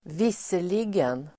Uttal: [²v'is:er_li(:)gen]